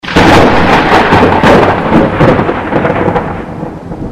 thunder.mp3